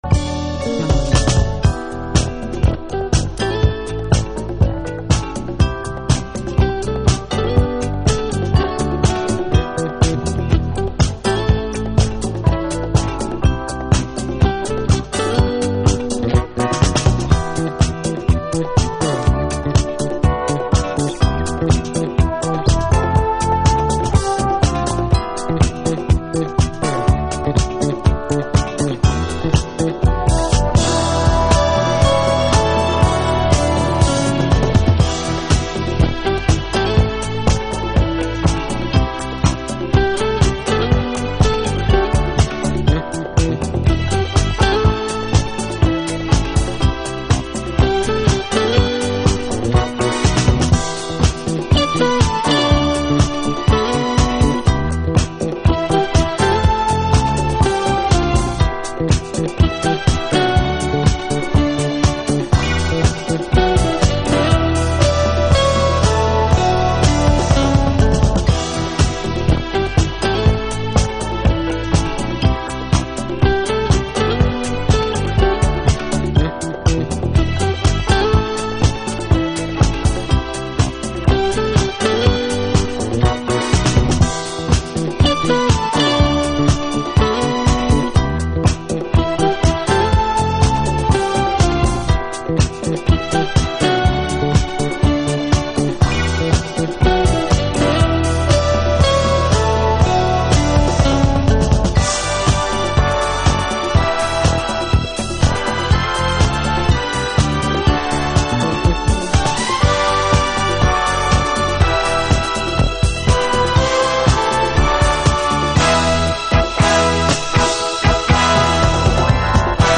Alt Disco / Boogie
脈々と続くリエディットという様式。